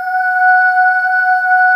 Index of /90_sSampleCDs/AKAI S6000 CD-ROM - Volume 1/VOCAL_ORGAN/BIG_CHOIR
AH DB4.WAV